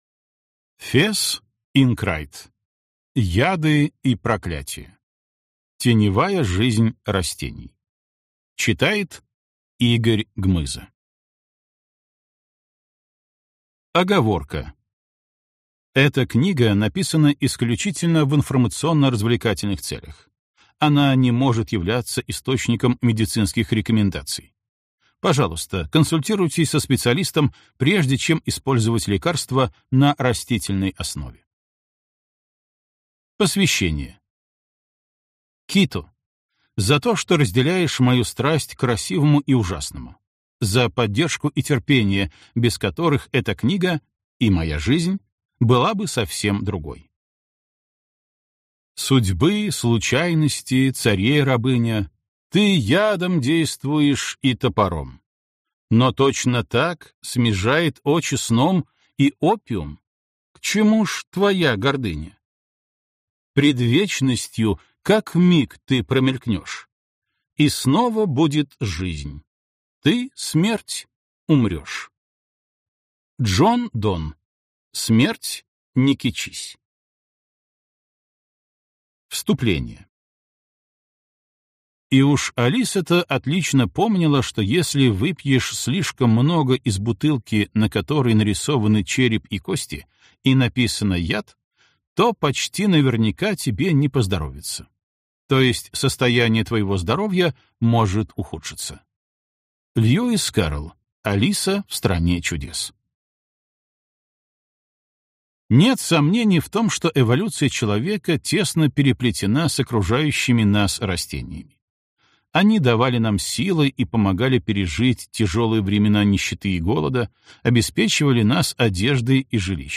Аудиокнига Яды и проклятия. Теневая жизнь растений | Библиотека аудиокниг